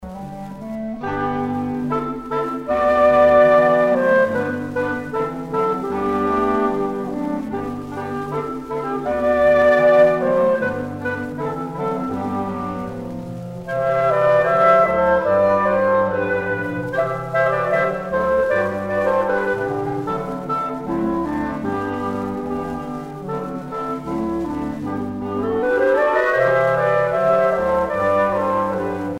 Usage d'après l'analyste gestuel : danse ;
Pièce musicale éditée